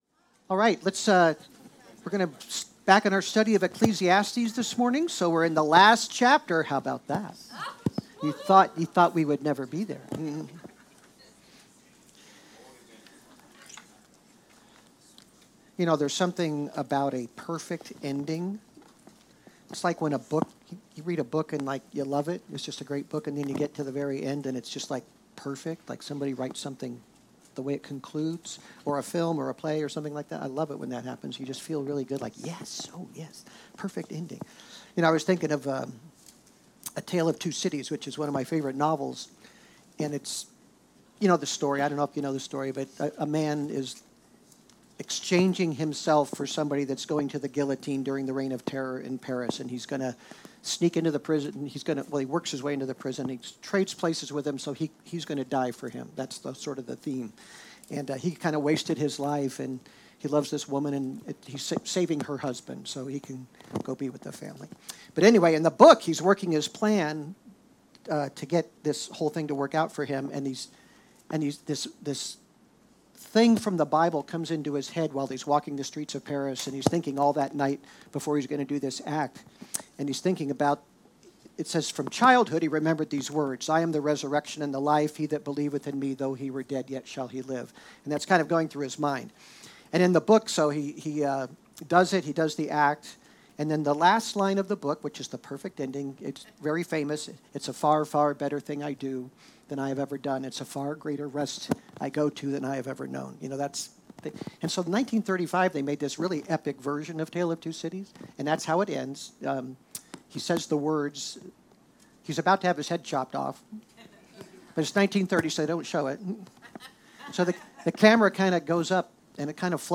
In the penultimate sermon in Ecclesiastes